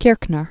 (kîrknər, kîr-), Ernst Ludwig 1880-1938.